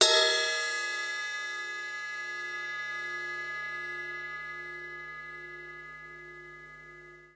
ride1Bell_f.mp3